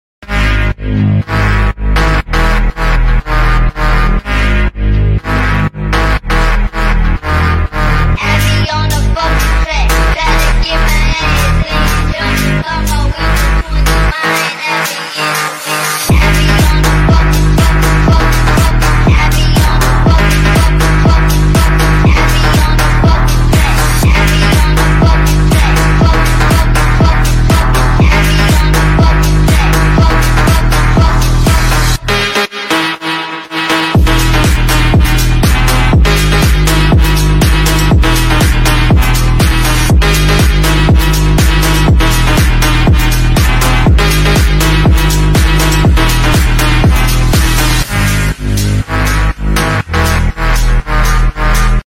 Phonk
(sped up)